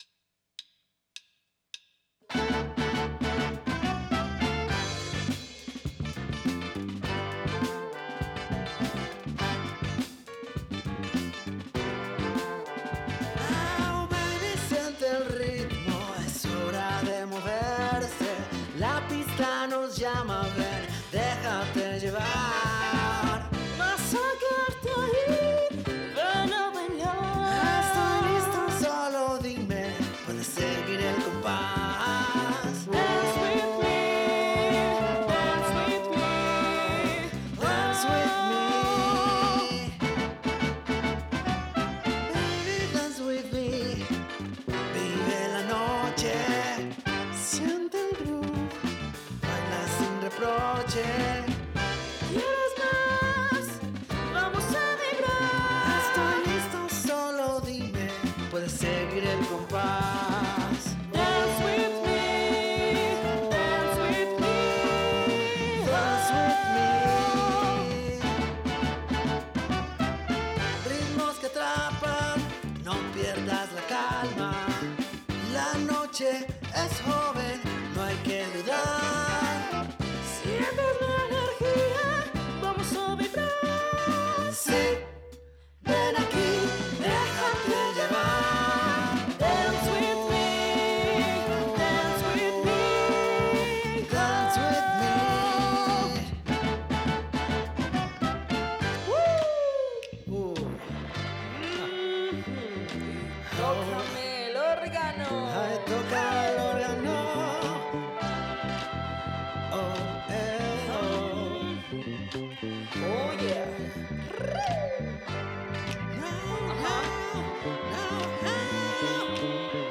Funk colombiano
Emulación analógica